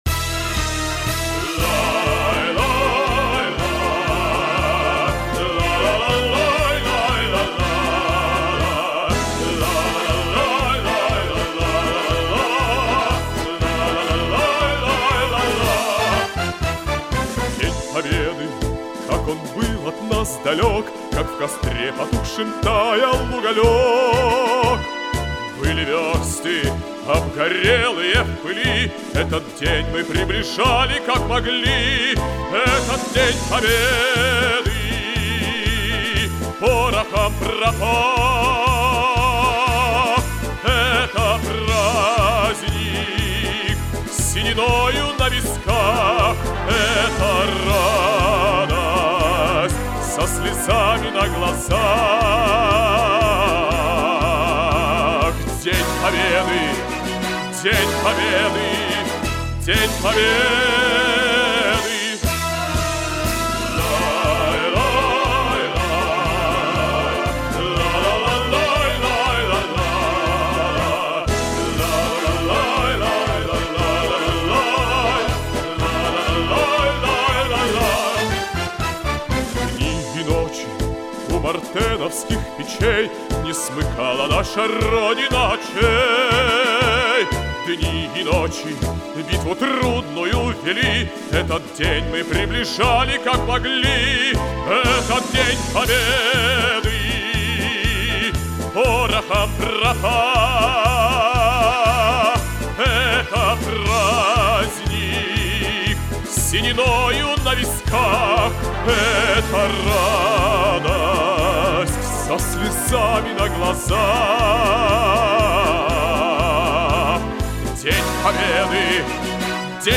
баритон